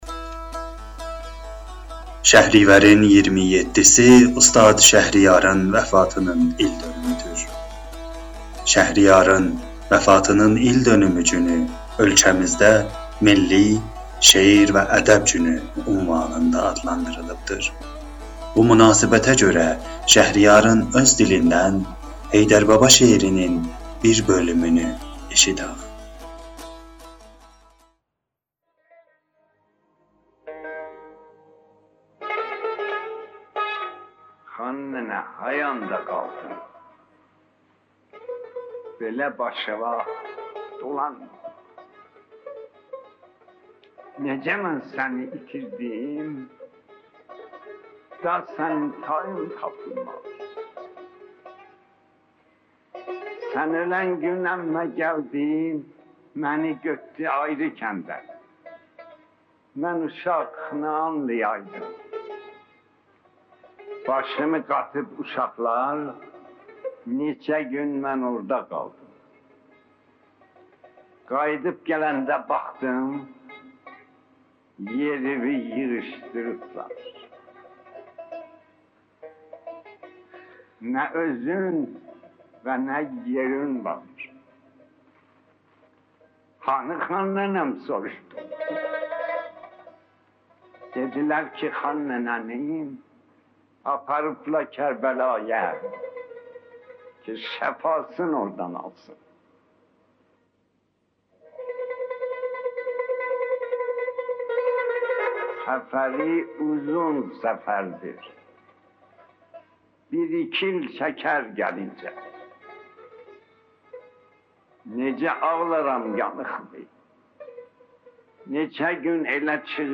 در پادکست زیر بخشی از منظومه حیدربابای شهریار را از زبان خودش بشنوید